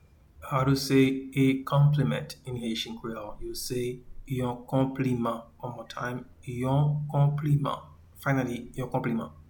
Pronunciation and Transcript:
a-Compliment-in-Haitian-Creole-Yon-konpliman.mp3